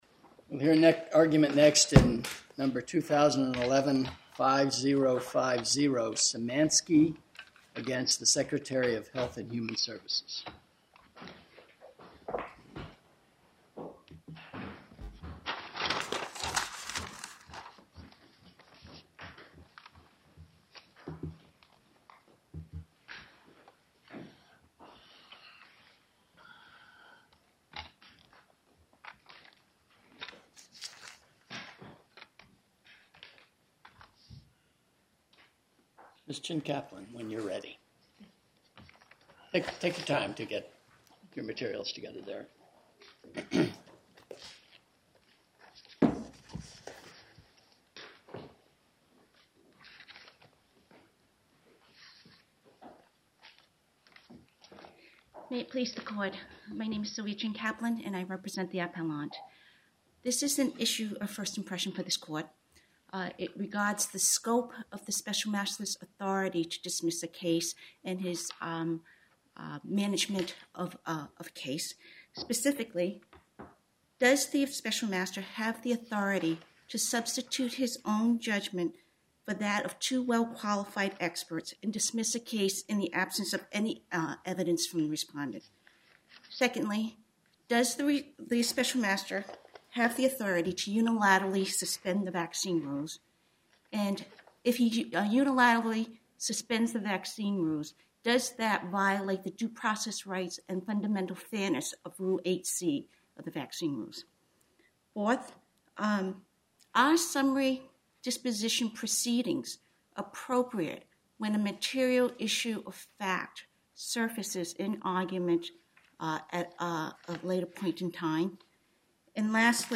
Oral argument audio posted